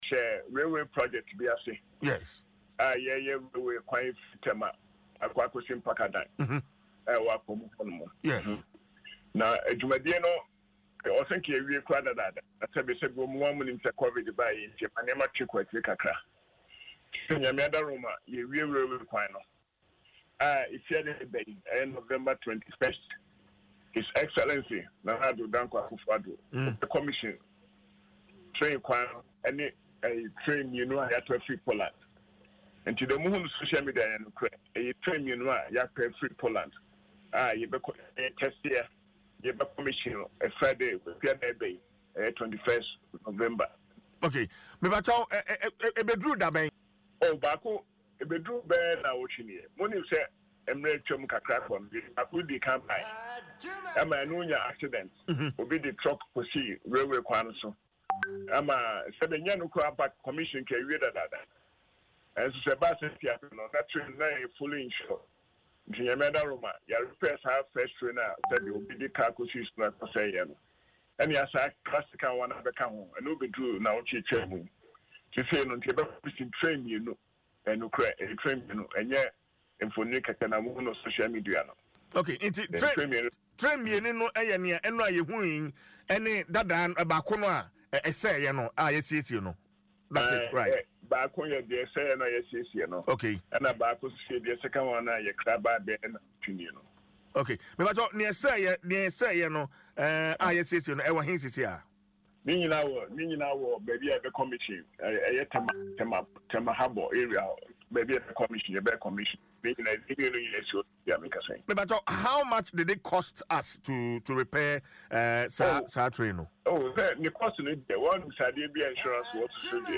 Chief Executive Officer of the Ghana Railway Development Authority (GRDA), Yaw Owusu, made this known in an interview on Adom FM’s Dwaso Nsem morning show.